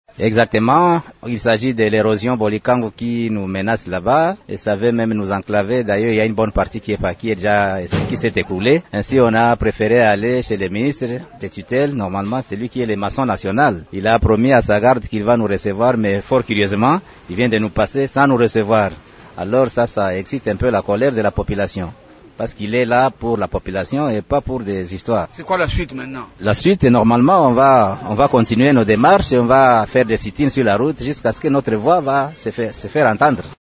un des notables du quartier Punda exprime leur désarroi :